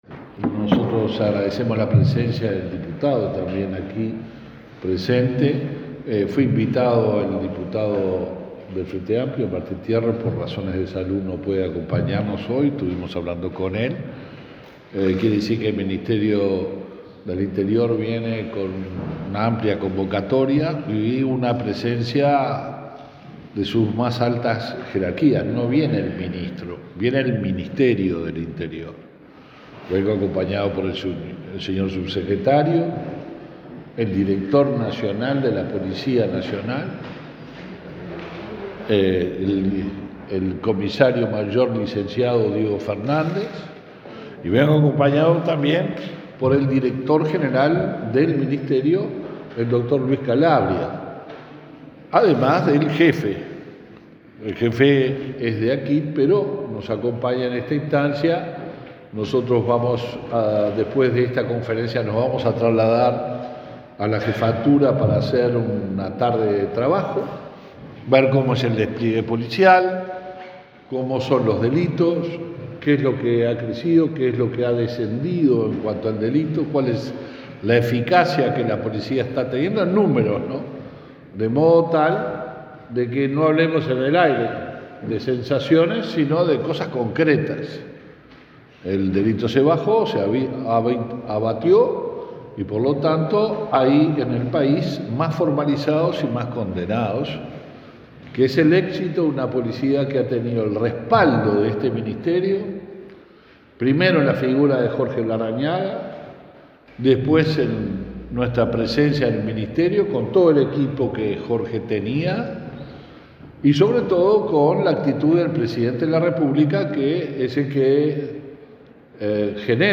Declaraciones del ministro del Interior, Luis Alberto Heber
El Ministerio del Interior inauguró una comisaría especializada en violencia doméstica y género en Durazno. En el evento participó el ministro Heber.